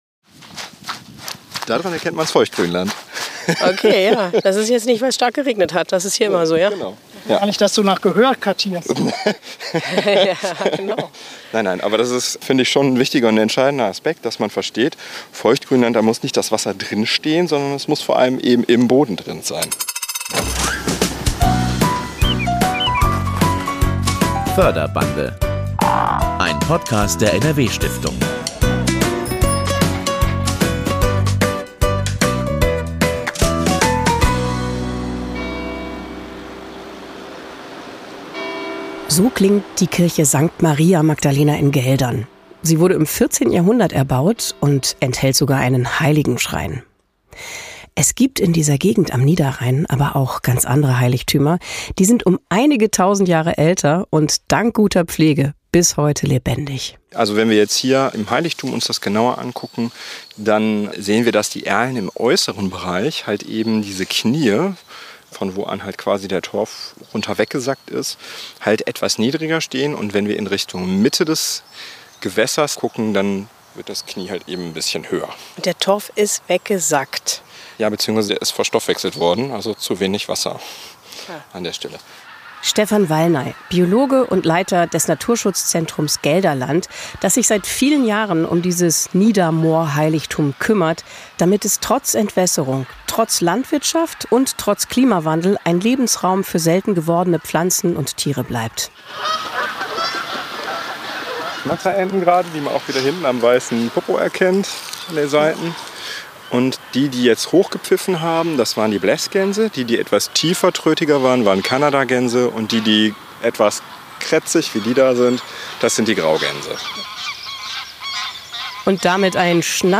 Studiogast